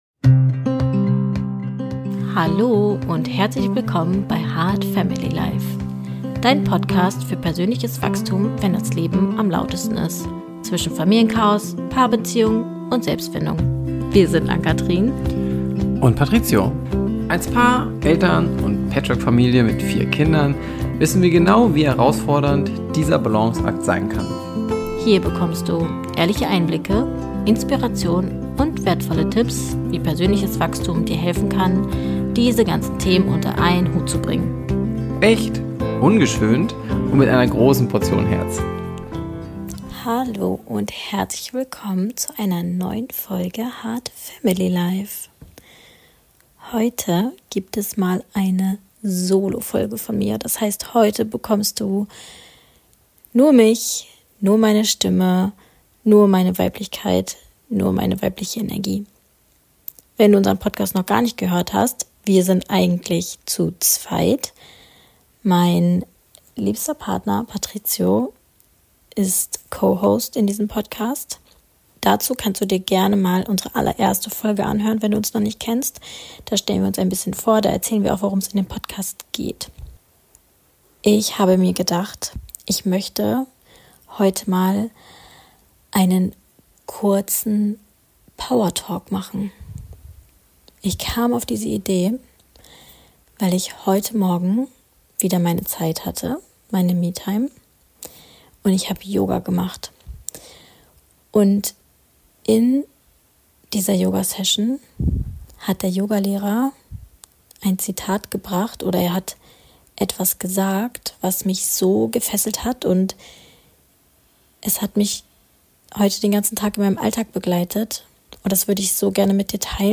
Heute nehme ich dich mit in einen ganz besonderen Moment: Meine erste Solo-Folge – ein PowerTalk direkt aus dem Herzen.